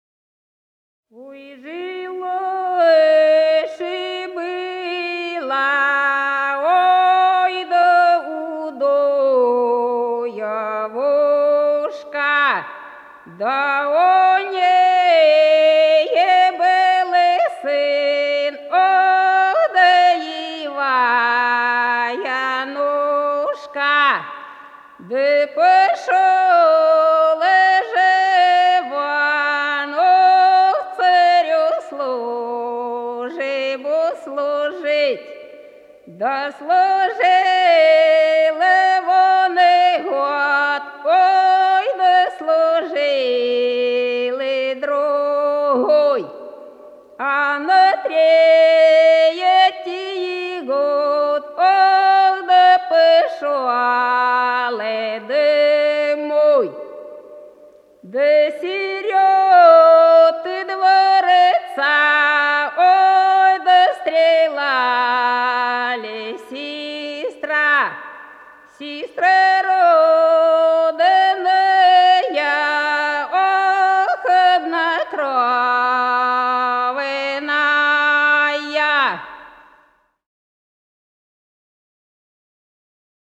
Голоса уходящего века (село Фощеватово) Ой, жила-была да удовушка
баллада